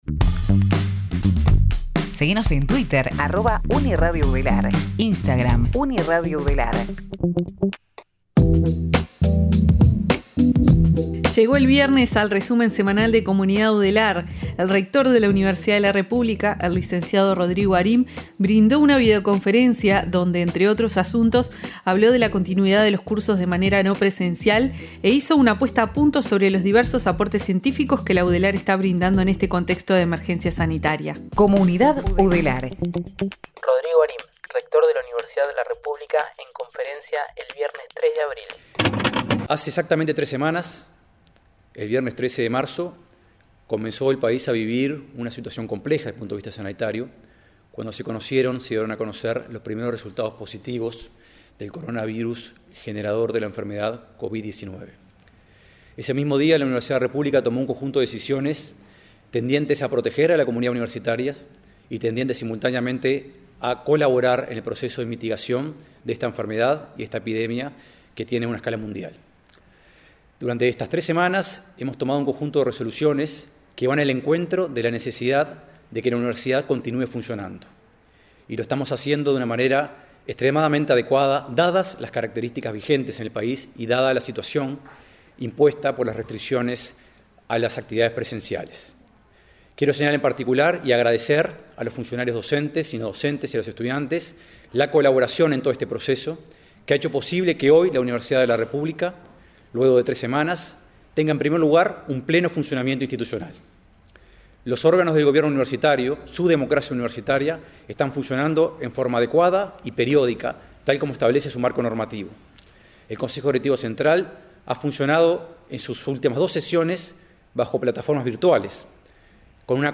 Compacto de noticias